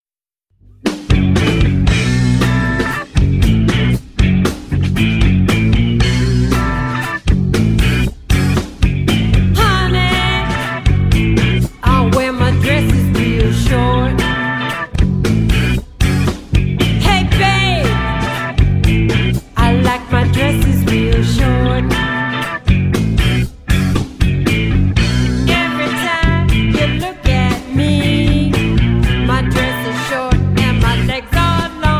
The musicianship is a powerhouse classic rock line up, funky